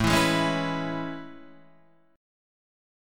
A Major 9th
AM9 chord {5 7 6 6 5 7} chord